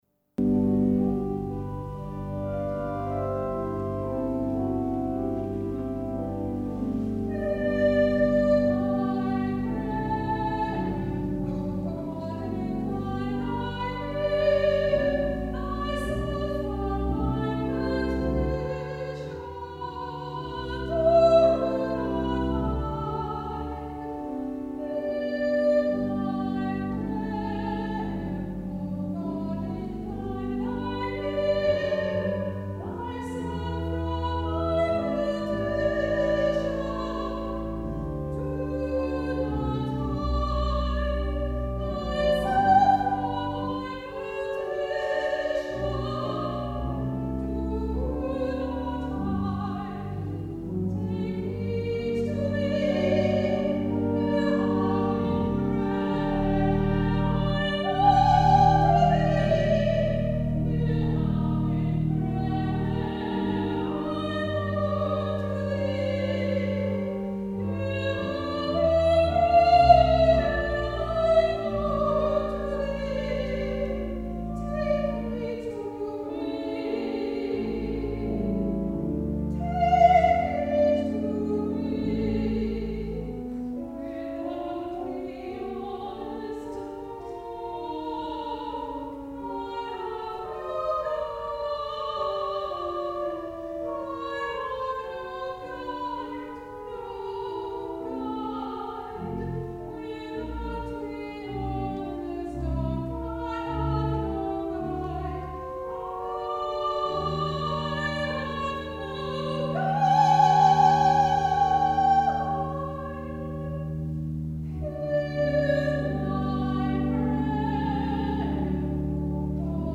SOLO: Hear My Prayer
soprano
organ